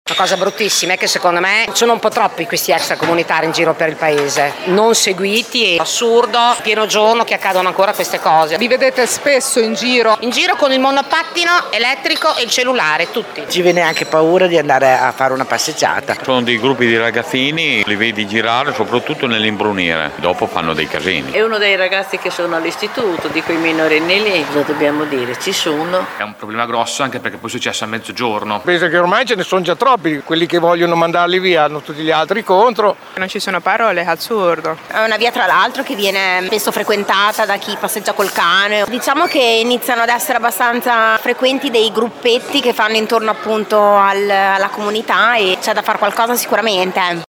Sotto choc i residenti della piccola frazione modenese: